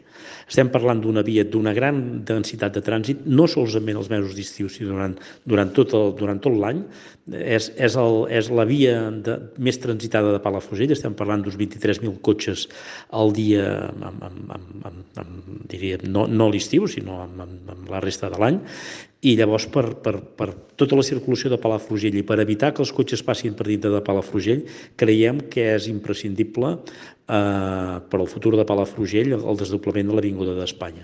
Josep Piferrer, alcalde de Palafrugell, ha dit a Ràdio Capital que es tracta d’un projecte que ells van “trobar sobre la taula i que era de l’anterior govern socialista”, i n’ha destacat la importància.